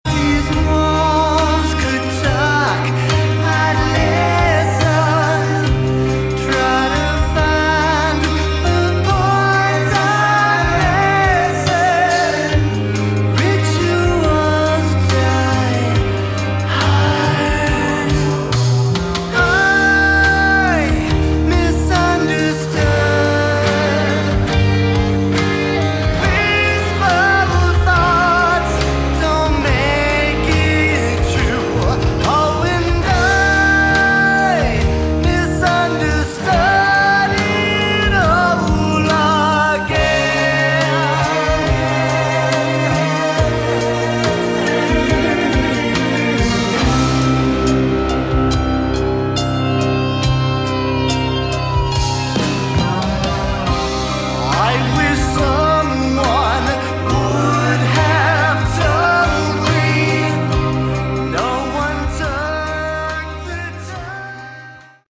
Category: Melodic Rock
vocals
guitar, bass, keyboards
drums